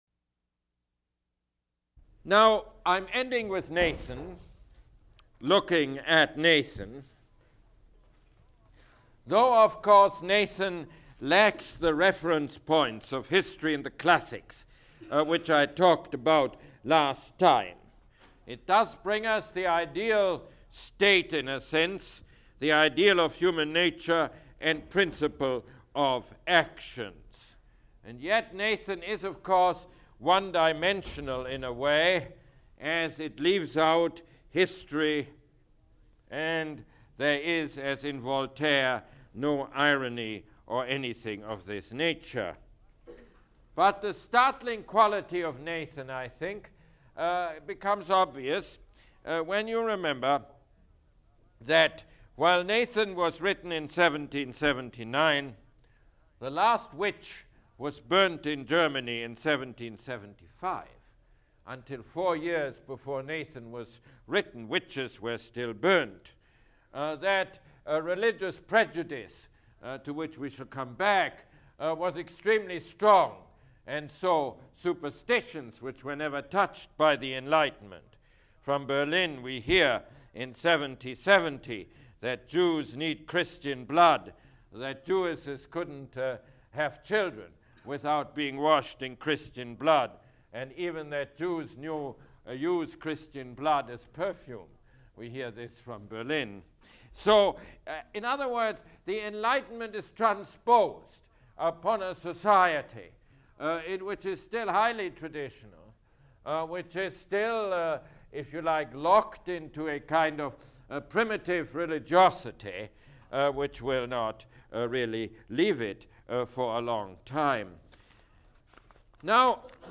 Mosse Lecture #8